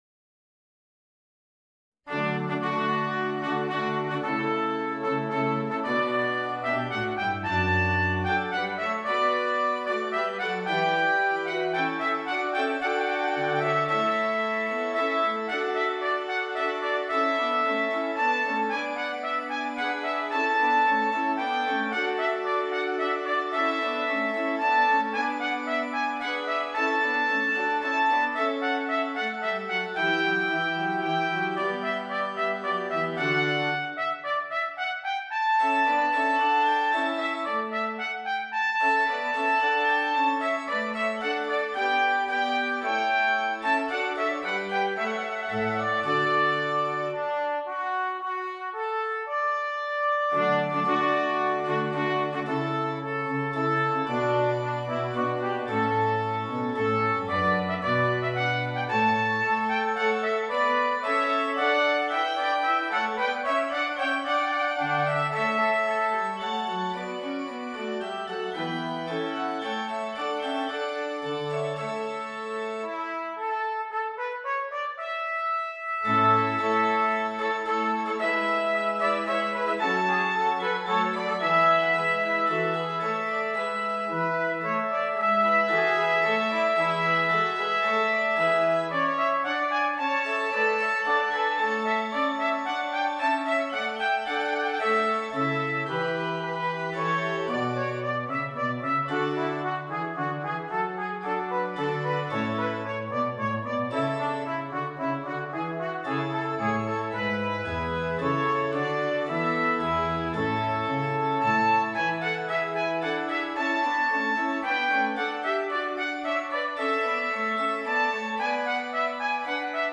2 Trumpets and Keyboard
This is a duet for 2 trumpets and keyboard (organ or piano).